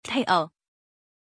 Pronuncia di Teo
pronunciation-teo-zh.mp3